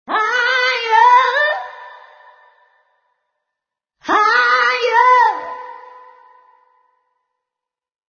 misc_vocal04.mp3